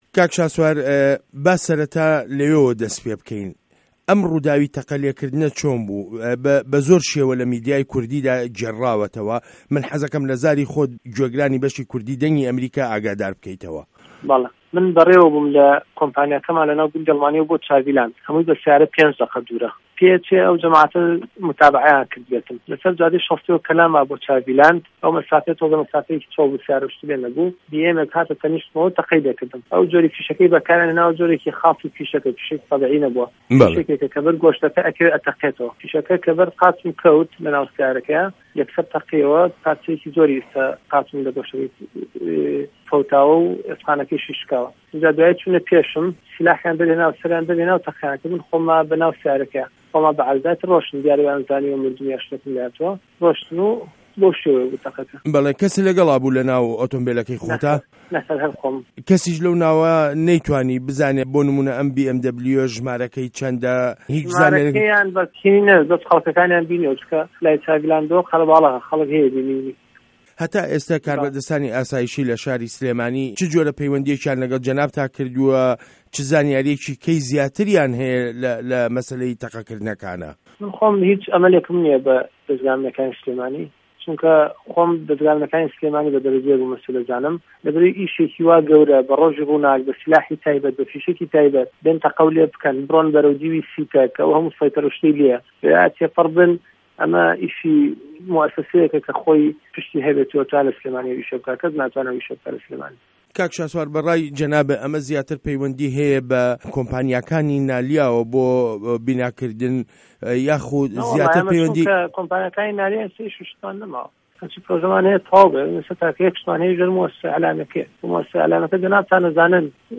وتووێژ له‌گه‌ڵ شاسوار عه‌بدولواحید